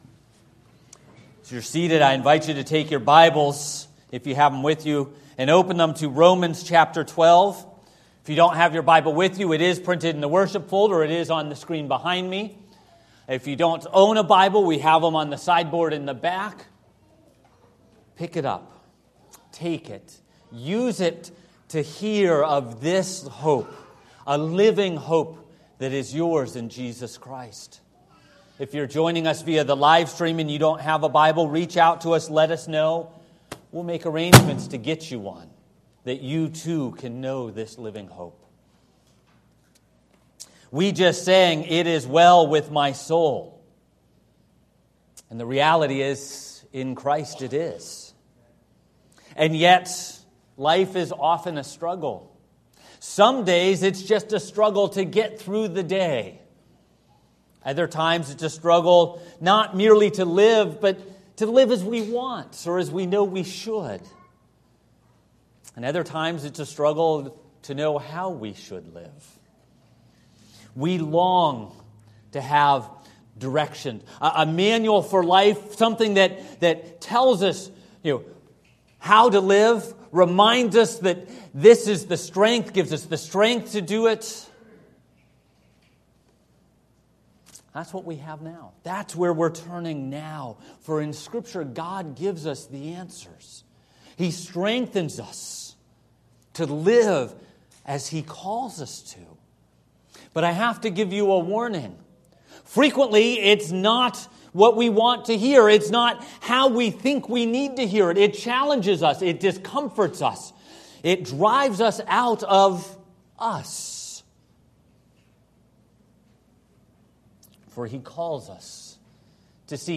A Presbyterian Church (PCA) serving Lewiston and Auburn in Central Maine